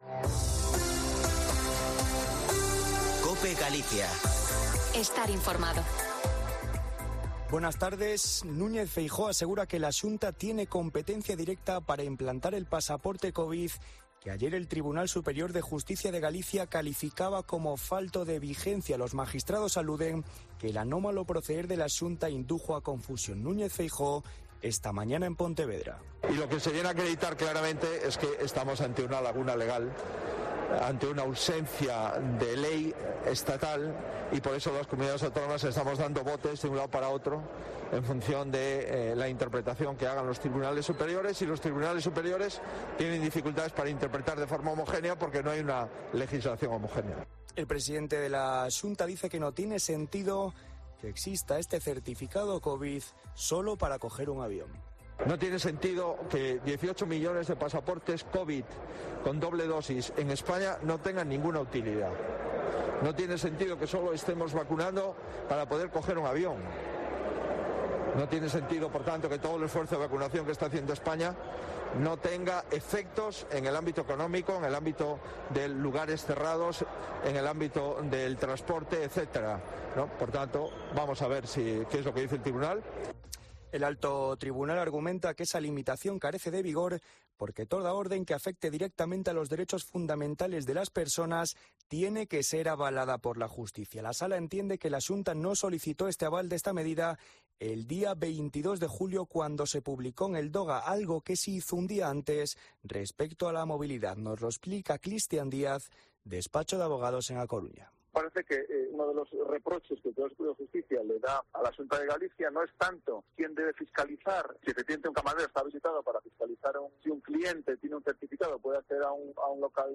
Informativo Mediodía en Cope Galicia 13/08/2021.